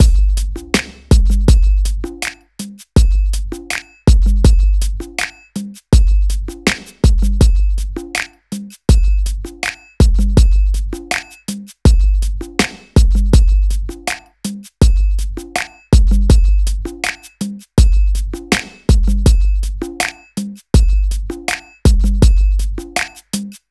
11 drums B.wav